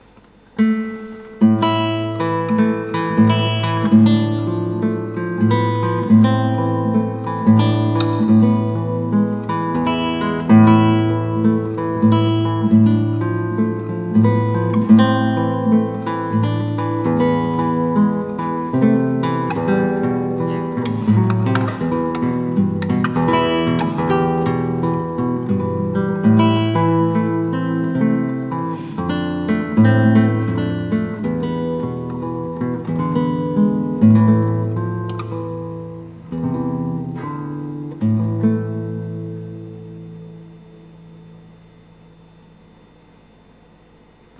Melodie: Es geht ein dunkle Wolk herein